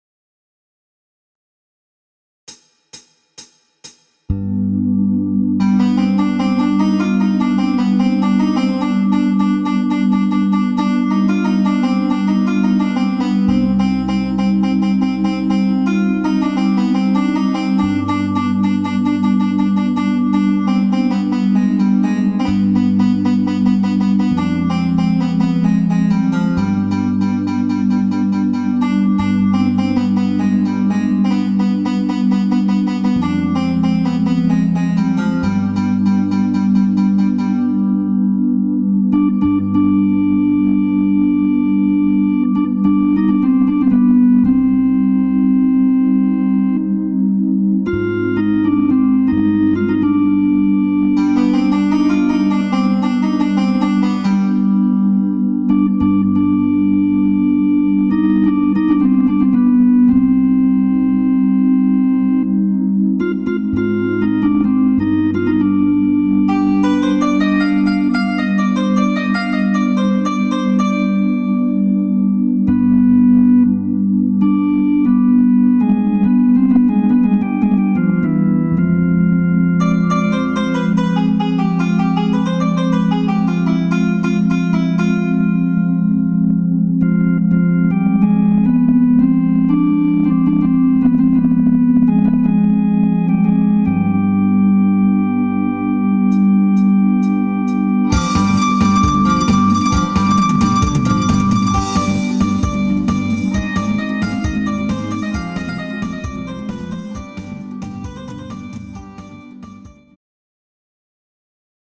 Instrumental international